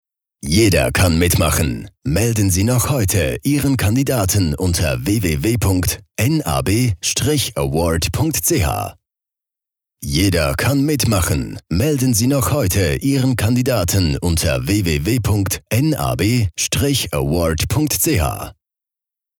Werbung Hochdeutsch (CH)
Sprecher mit breitem Einsatzspektrum.